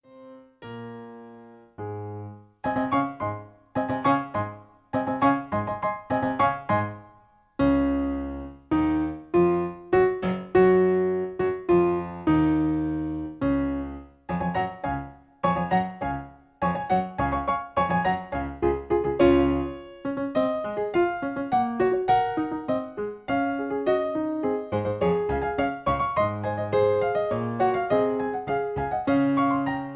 In D flat. Piano Accompaniment